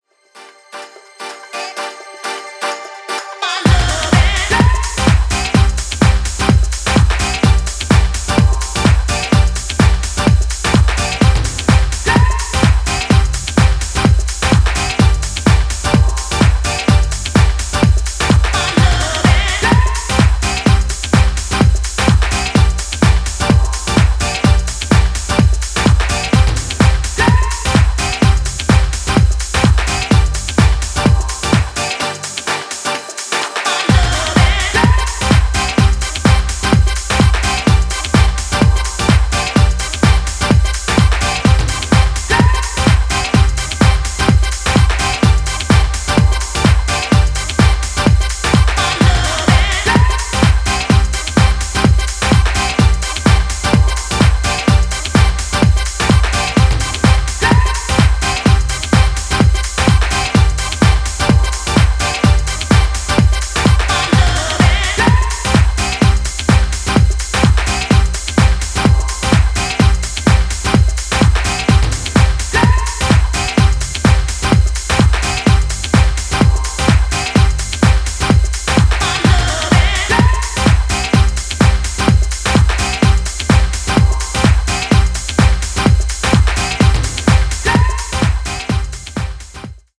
dancefloor-ready tracks
House